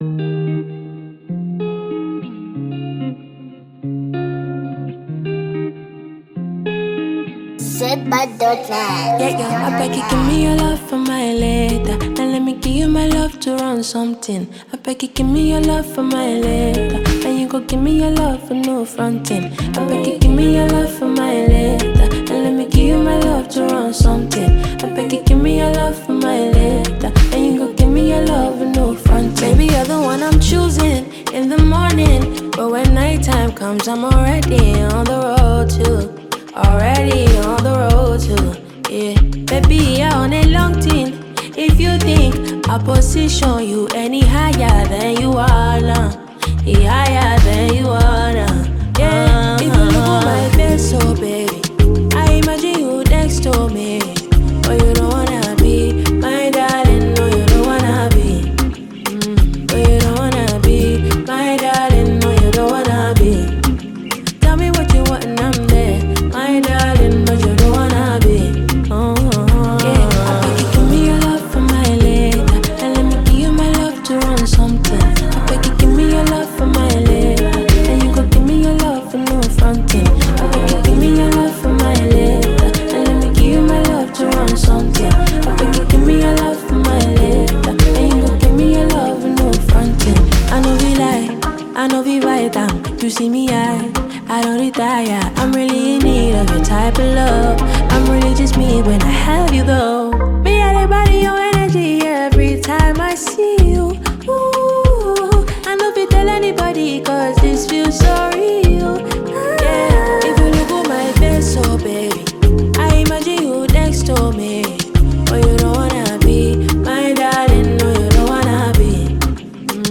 Genre: Afrobeat
Category: Nigerian Music